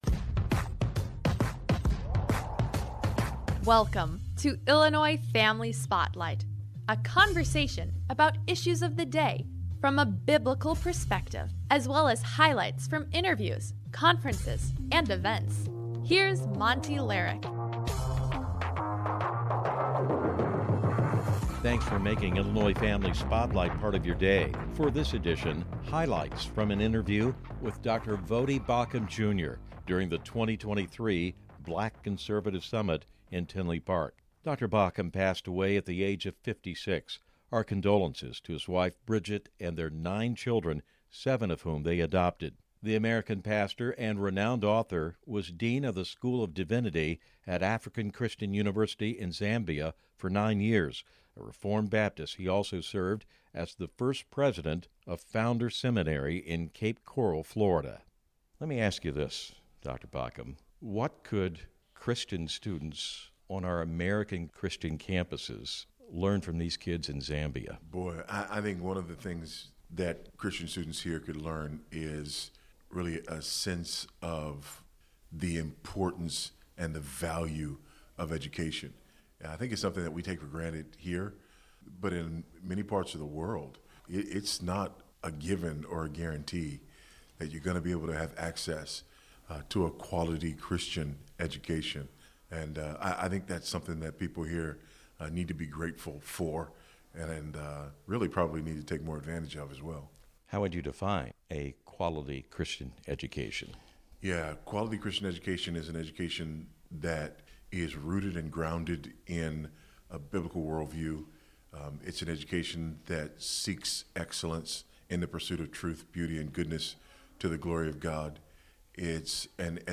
This episode features highlights from an interview with Dr. Voddie Baucham, which took place during the 2023 Black Conservative Summit in Tinley Park.